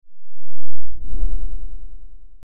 Download Low Frequency sound effect for free.
Low Frequency